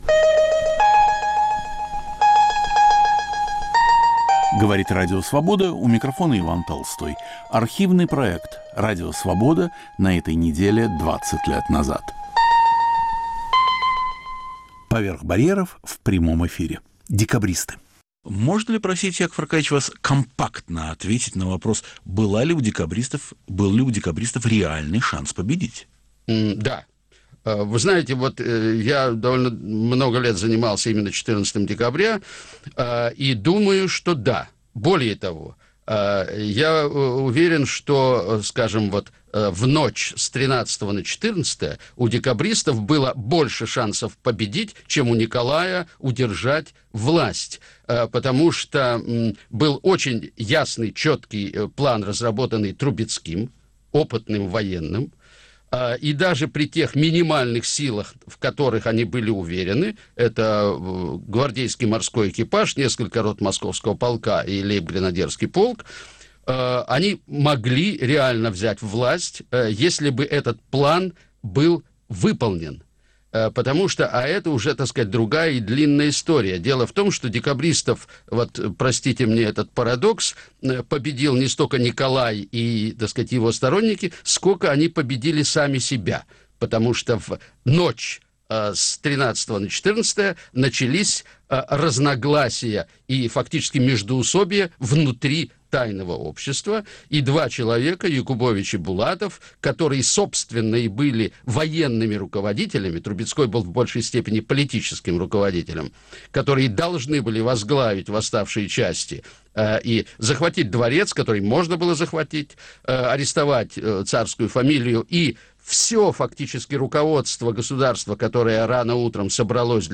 Был ли у декабристов шанс победить? В студии Радио Свобода писатель и историк Яков Гордин. Ведущий Иван Толстой.